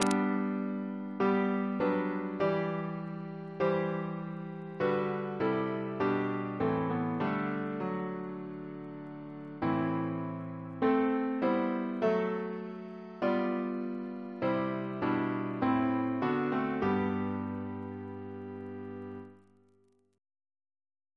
Double chant in G Composer: Sir Edward C. Bairstow (1874-1946), Organist of York Minster Reference psalters: ACP: 236